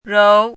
“柔”读音
róu
柔字注音：ㄖㄡˊ
国际音标：ʐou˧˥